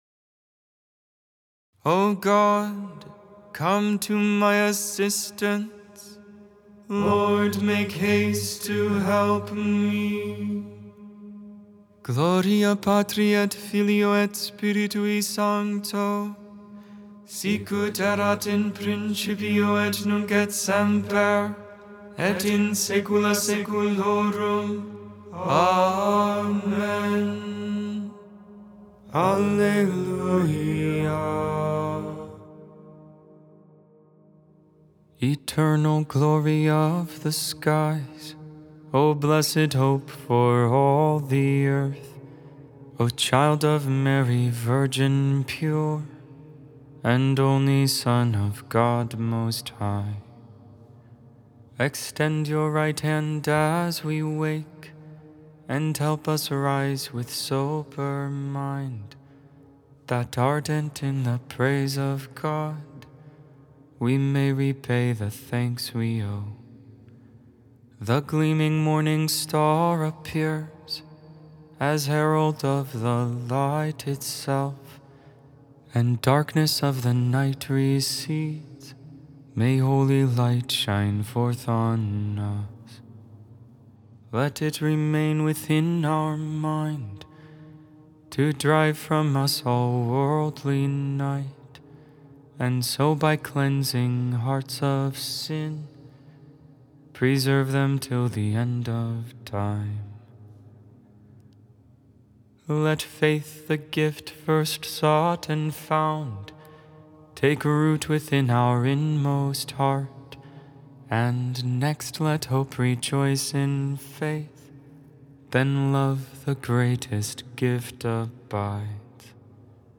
Lauds, Morning Prayer for the 33rd Friday in Ordinary Time, November 22, 2024.